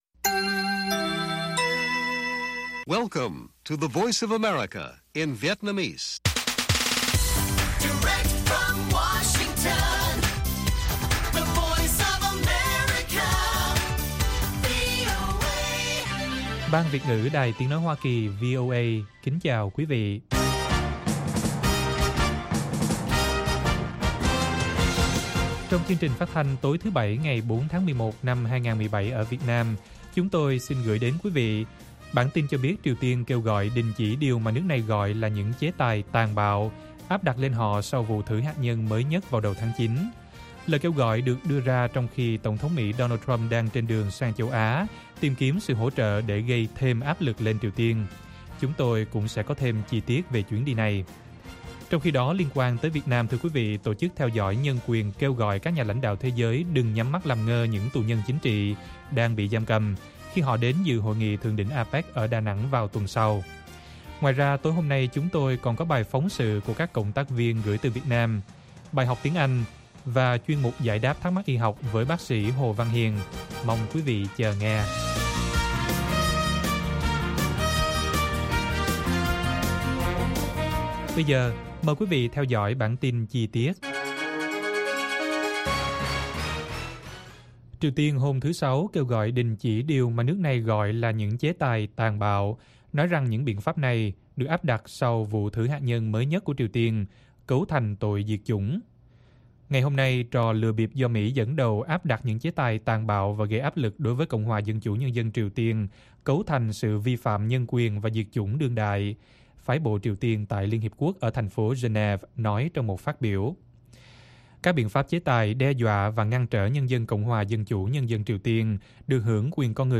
Các bài phỏng vấn, tường trình của các phóng viên VOA về các vấn đề liên quan đến Việt Nam và quốc tế, và các bài học tiếng Anh.